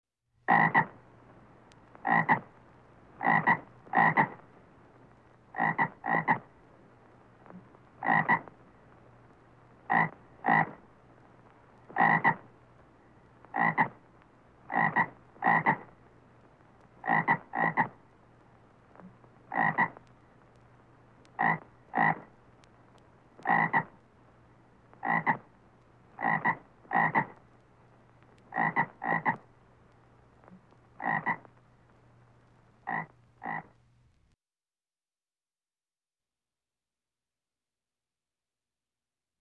Звуки жабы
Квакающий звук жабы